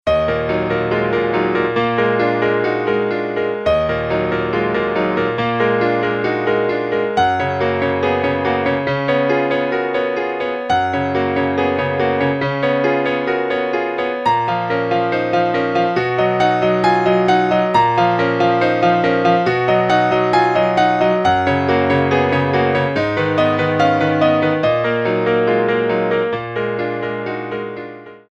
Стандартные рингтоны